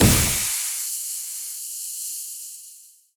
poly_explosion_smoke.wav